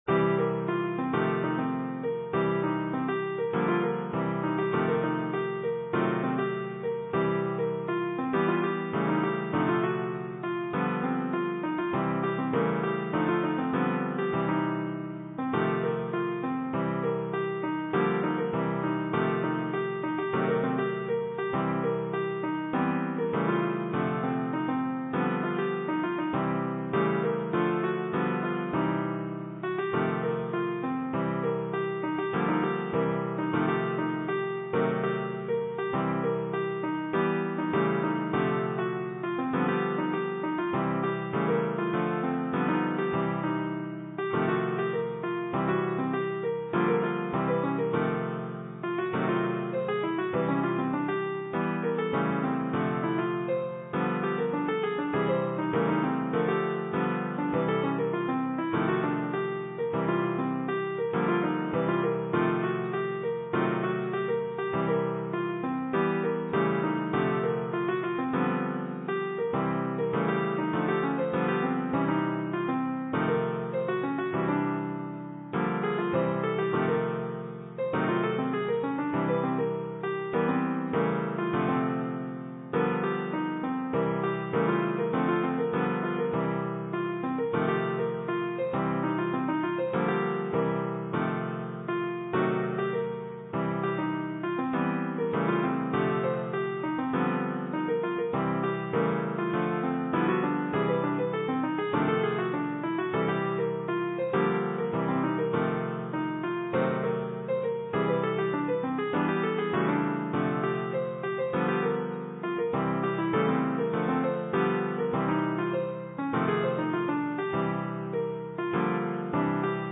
These compositions were made by an LSTM recurrent neural network.
Chords: LSTM was trained using a form of blues common in jazz bebop improvisation.
The improvisations were based around the pentatonic scale:
MP3 lo-res (1.4Mb), MIDI (22Kb)]: This second example shows how the network can drift from fairly close reproduction of training set melodies to freer improvisation. Listen from the beginning and notice how at around 0:14 the network begins reproducing the melody with some variation. At around 0:50 the network drifts somewhat from the melody and recovers at around 1:00. Then at 1:13 it begins to alternate between constrained and freer improvisation.